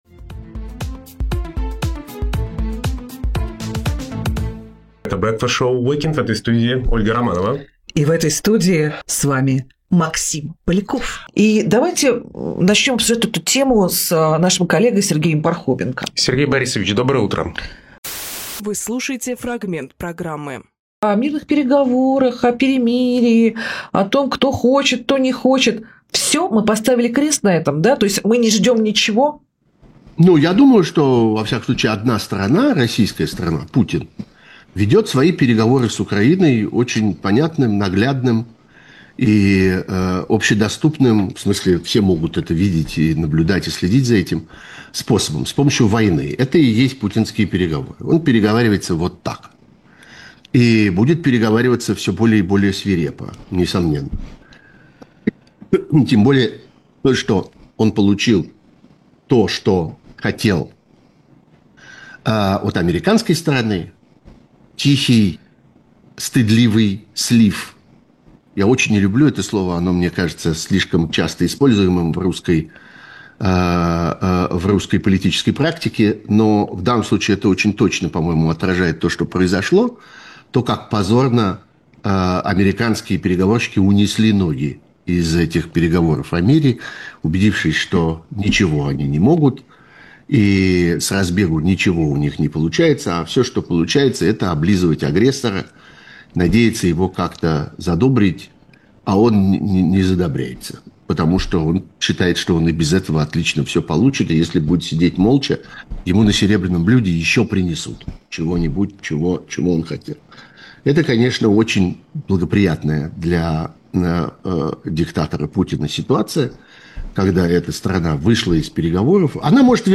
Сергей Пархоменкожурналист, политический обозреватель
Фрагмент эфира от 25.05.25